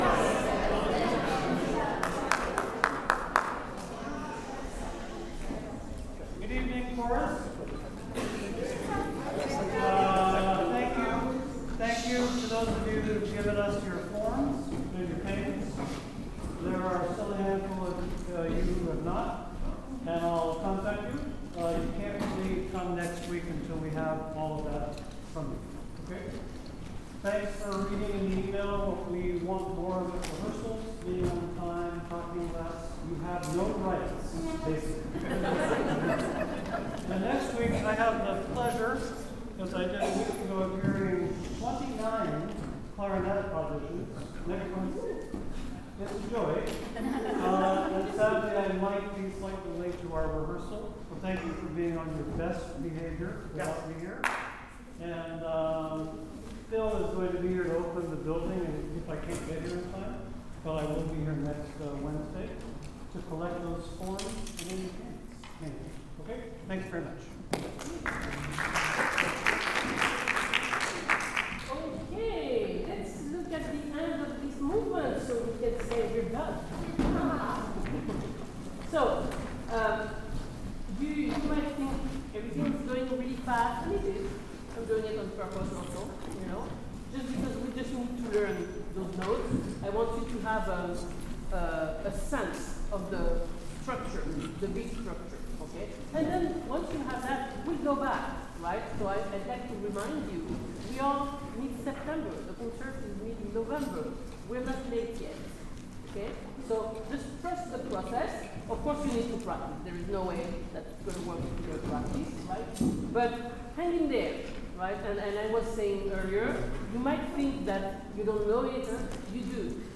OSC Rehearsal, 10 September 2025